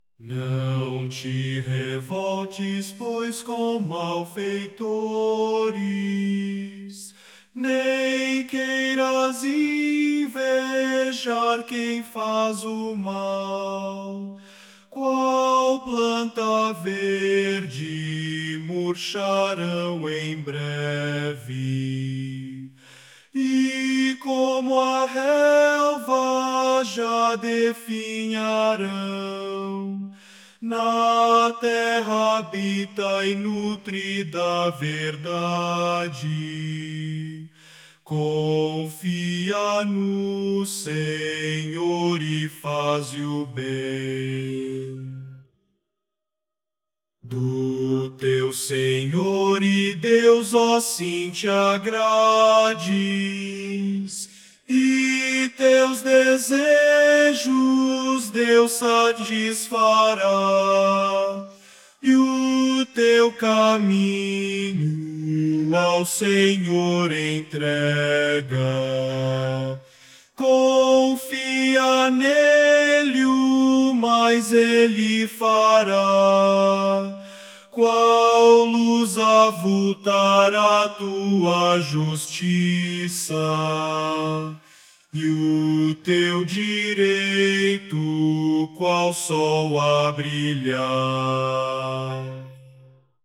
Modo: dórico
salmo_37A_cantado.mp3